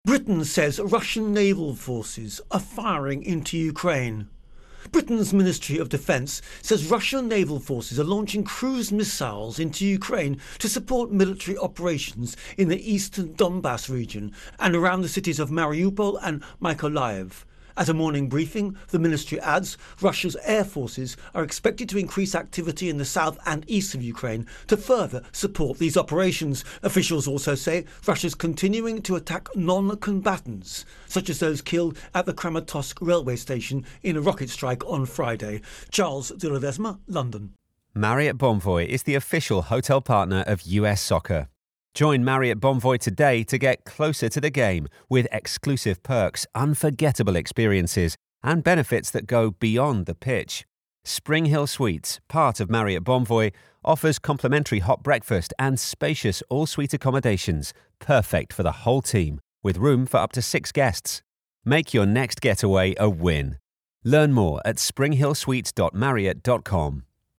Intro and Voicer